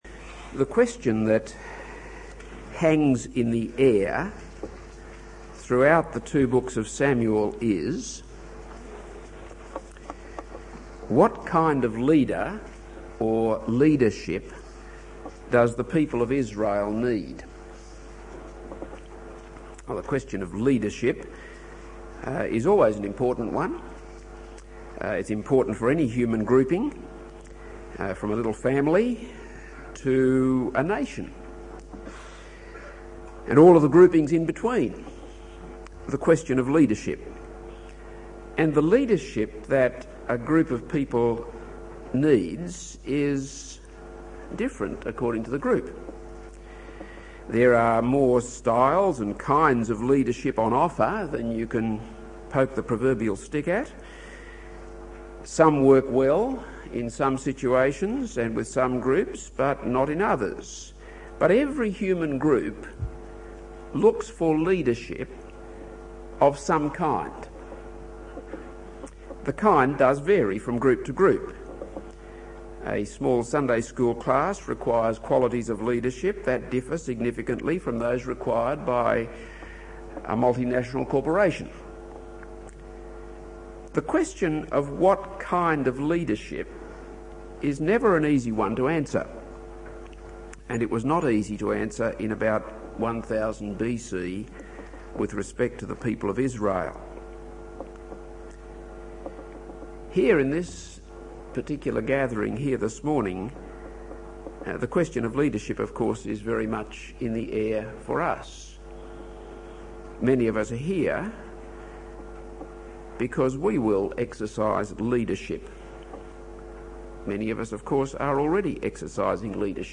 This is a sermon on 1 Samuel 27.